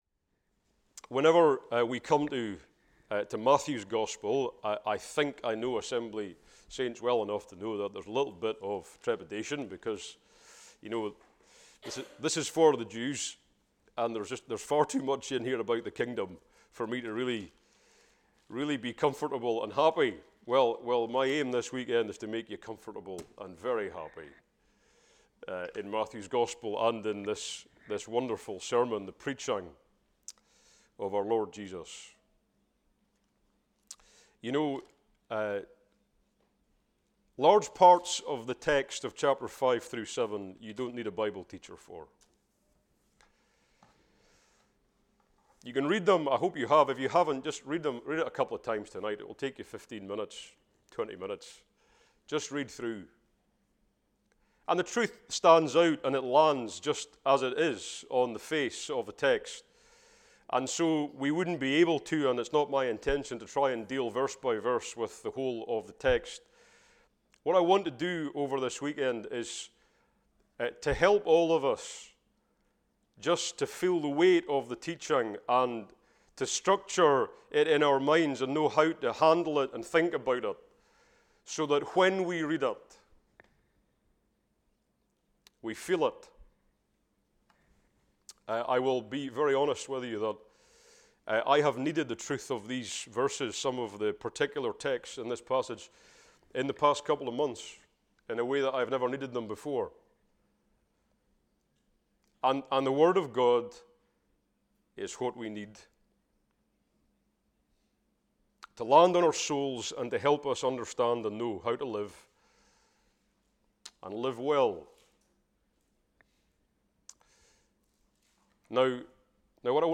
(Message preached 7th October 2022)